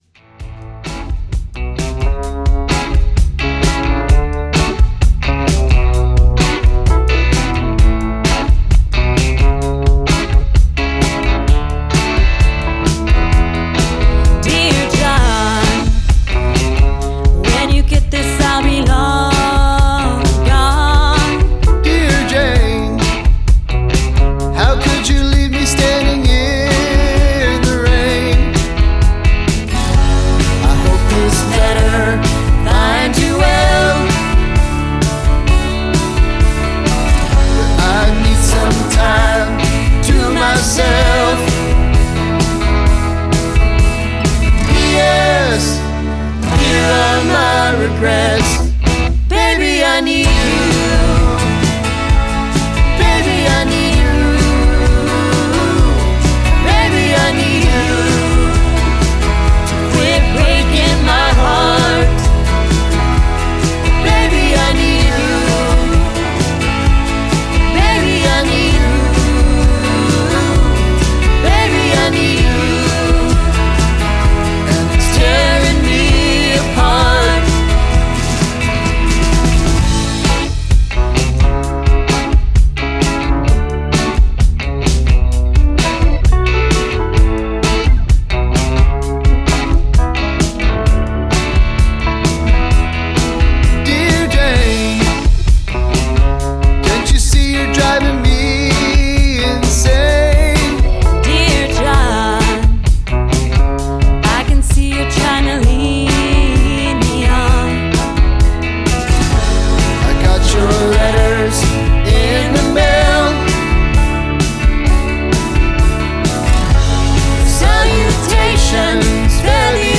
Alt-Country, Americana, Folk